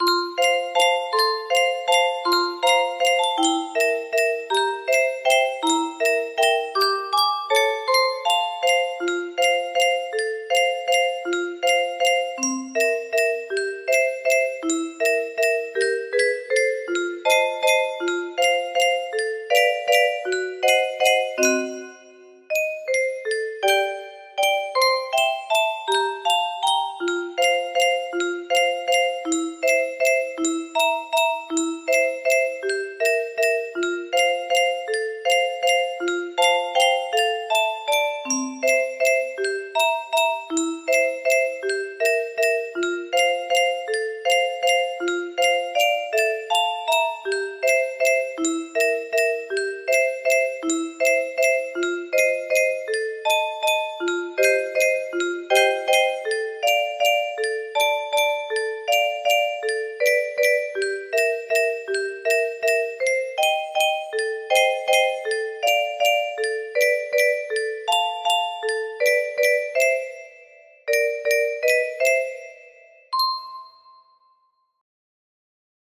UnBeauMane-FaMaj music box melody